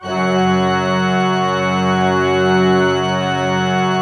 Index of /90_sSampleCDs/Propeller Island - Cathedral Organ/Partition I/PED.V.WERK R